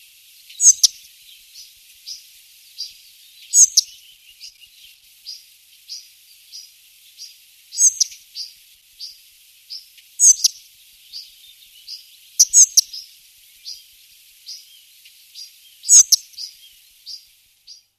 Muchołówka szara - Muscicapa striata